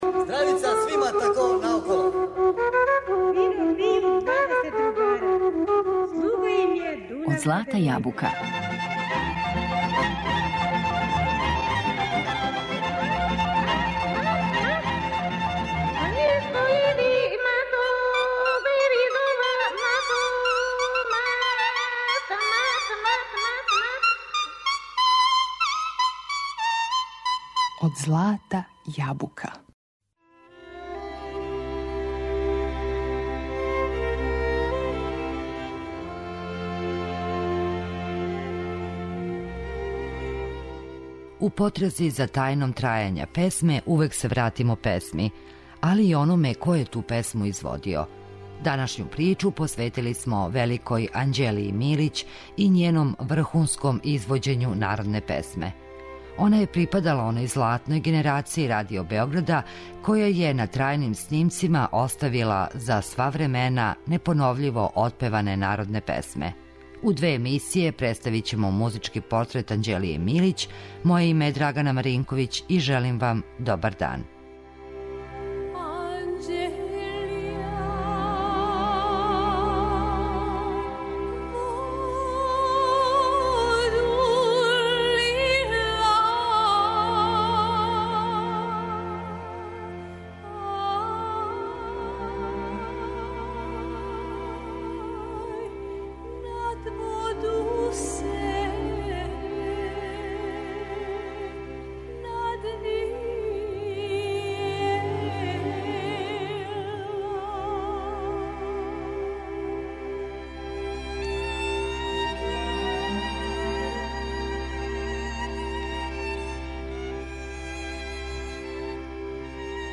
Припадала је златној генерацији певача Радио Београда, која је на трајним снимцима оставила, за сва времена, непоновљиво отпеване народне песме.